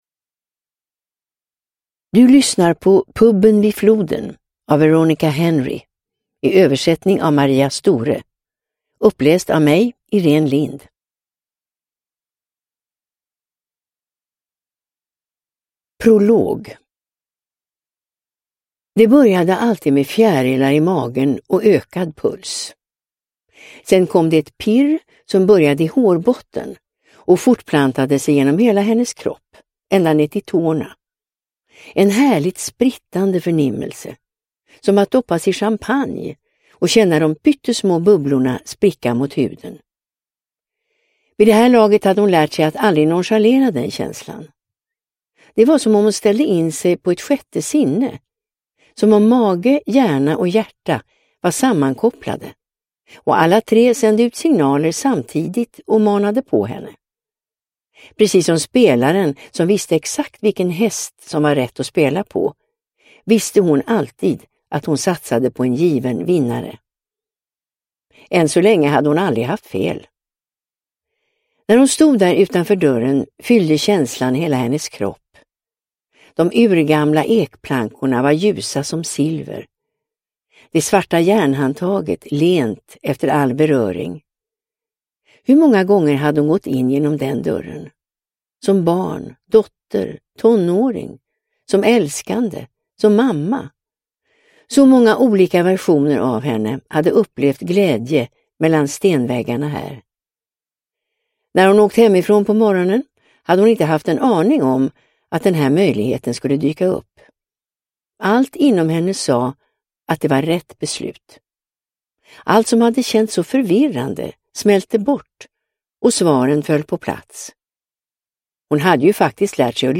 Puben vid floden – Ljudbok – Laddas ner